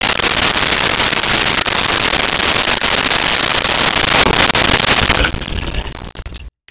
Sink Fill 03
Sink Fill 03.wav